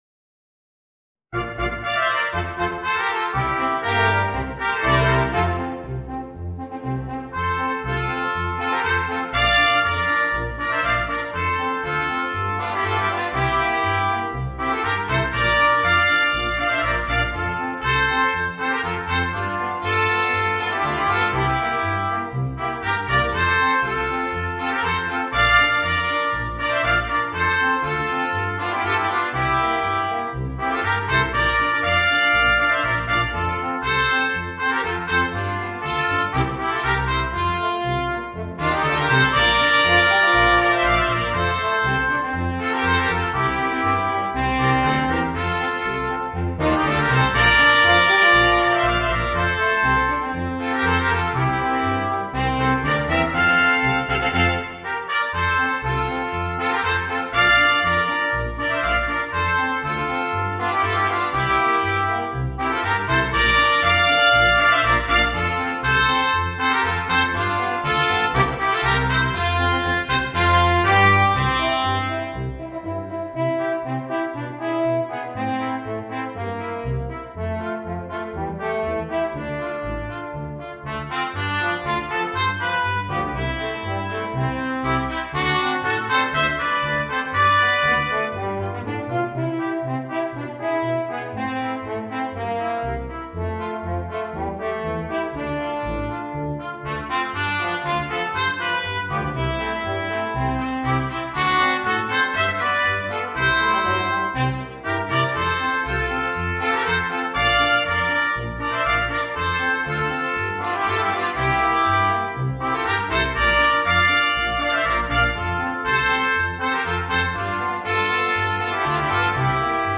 Gattung: Brass Quartet
Besetzung: Ensemblemusik für 4 Blechbläser